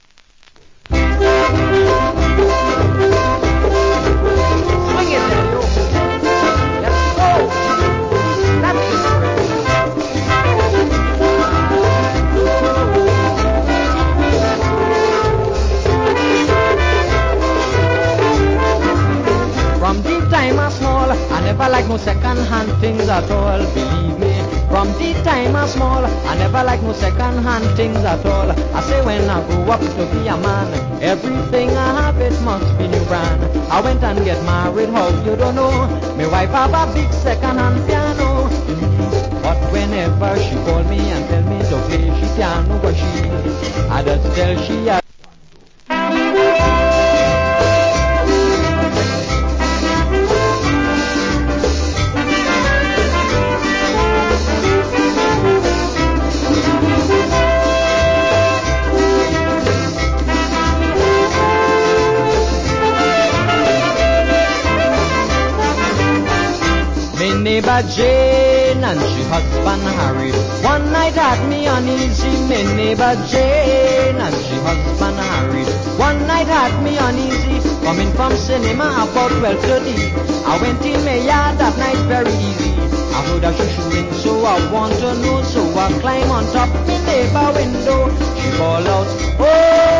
Wicked Calypso Vocal.